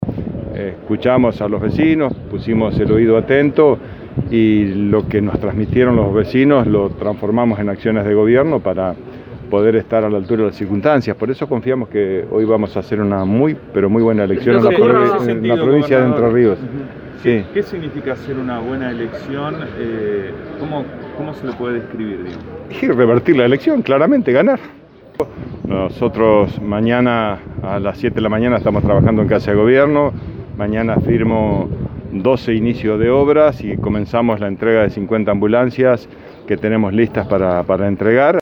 Hoy a la mañana en Concordia, el Contador Bordet dio declaraciones a la prensa.